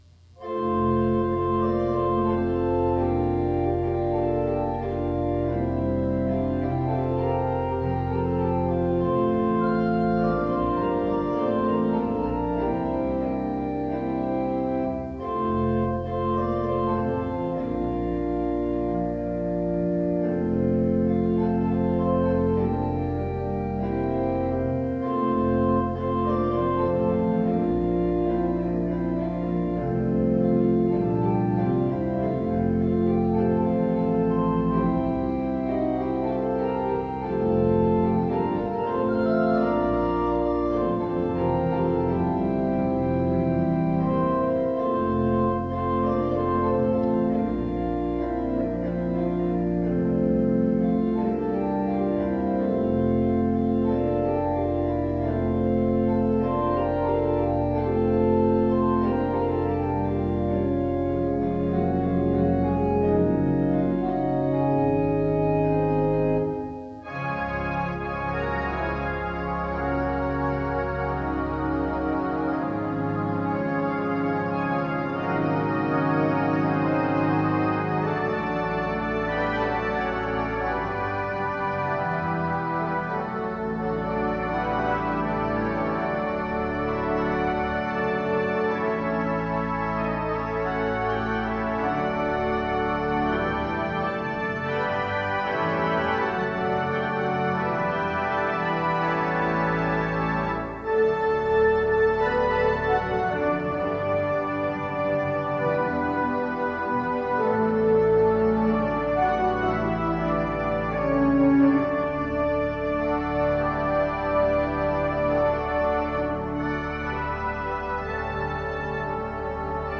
By Organist/Pianist